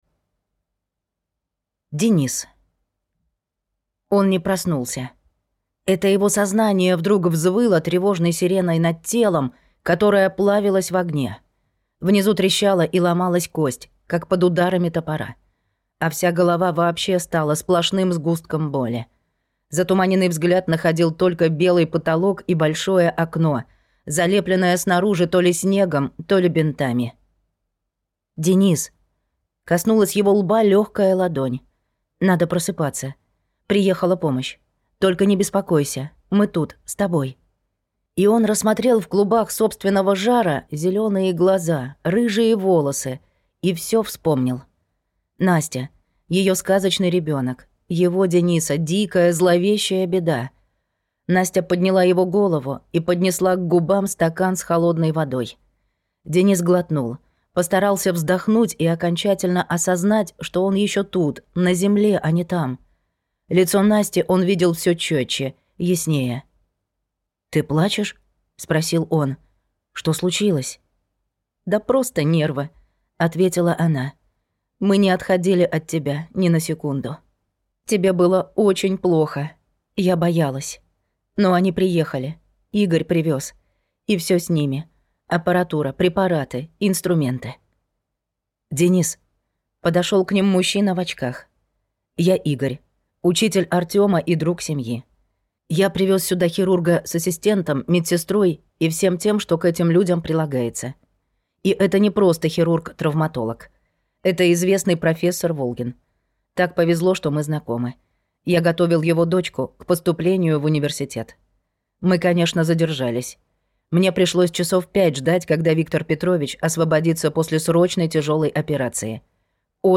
Слушать аудиокнигу Перевод с особого полностью